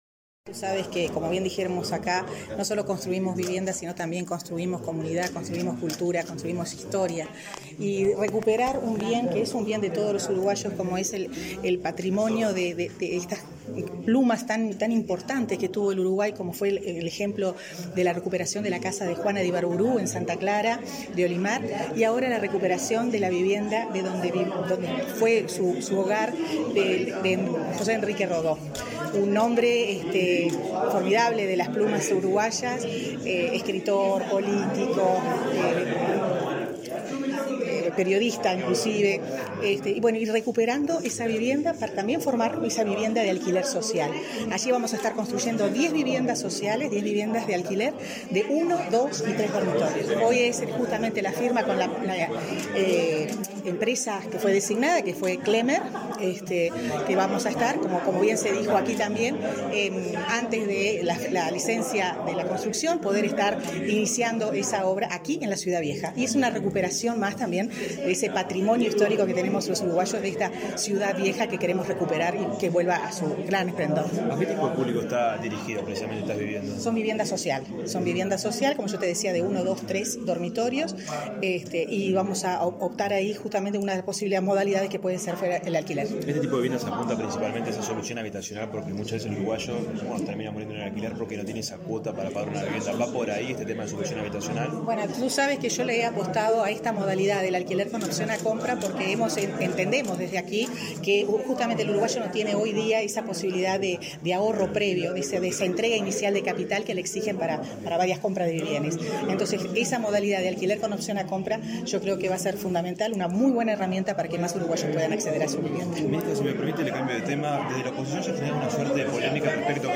Declaraciones de la ministra de Vivienda, Irene Moreira
El Ministerio de Vivienda y Ordenamiento Territorial, a través del programa Mejoramiento de Barrios, edificará casas en el barrio Ciudad Vieja, de Montevideo, destinadas a alquiler. Con este fin, este miércoles 5, la titular de la cartera, Irene Moreira, suscribió un acuerdo con representantes de la empresa Clemer y luego dialogó con la prensa.